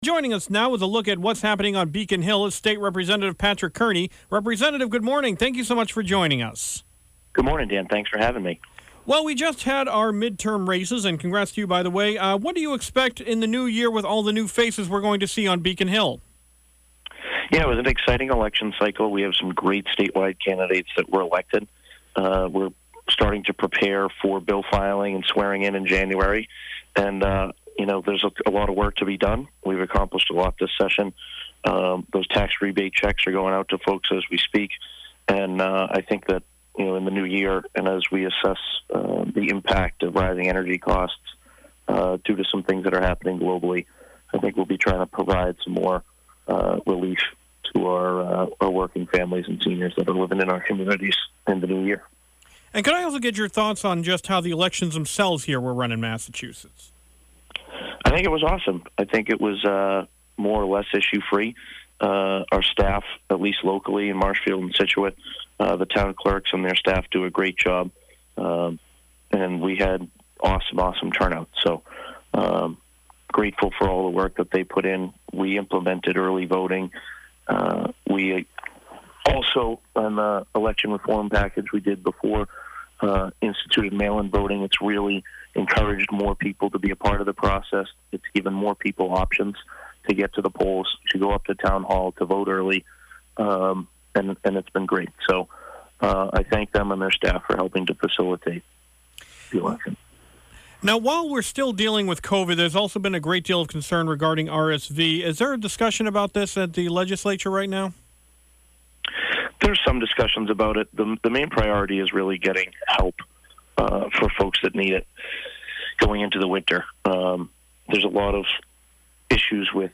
State Representative Patrick Kearney speaks